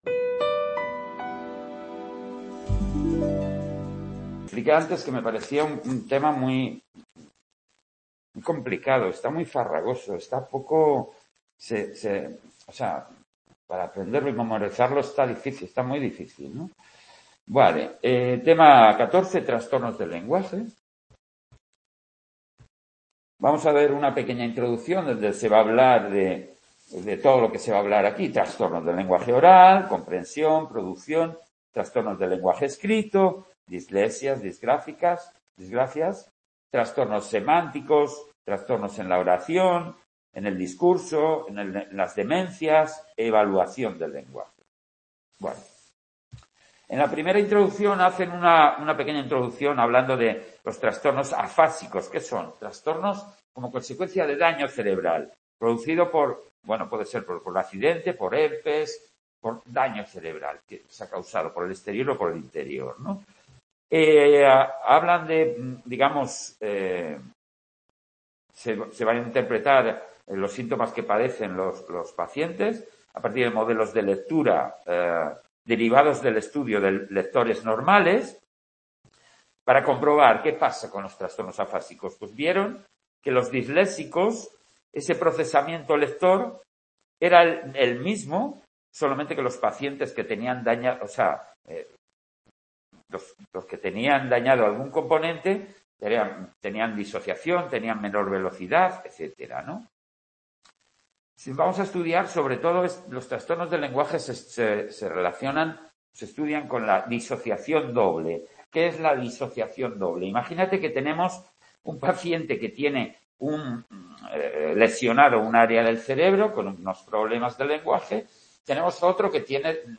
Tema 14, de Psicología del Lenguaje. "Trastornos del Lenguaje" . Grabación realizada en el Centro Asociado de Sant Boi